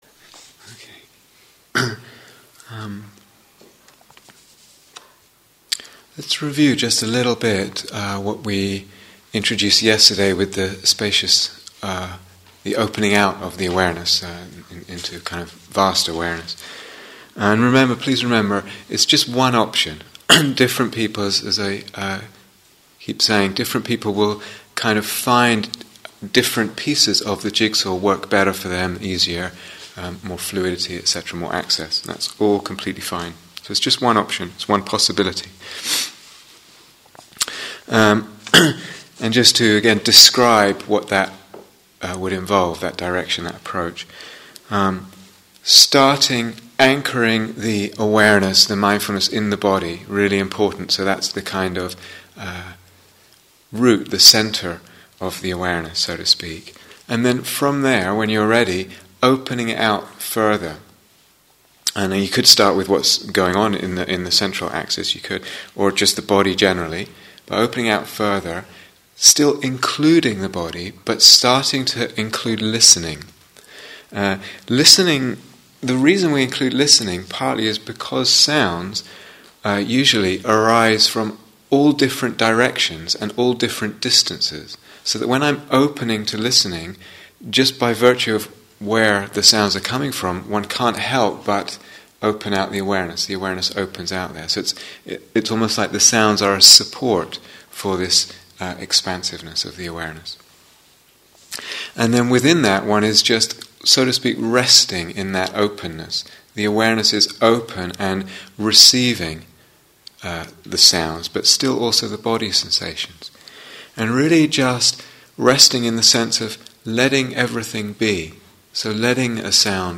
Working with the Emotional Body (Instructions and Guided Meditation: Day Five)